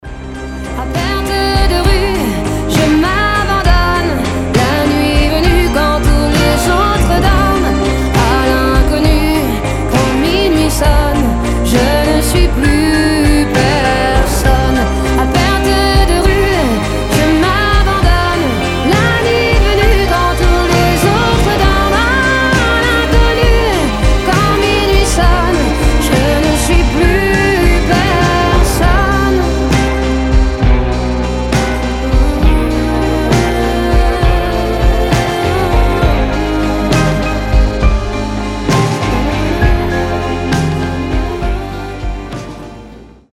• Качество: 320, Stereo
красивые
грустные
женский голос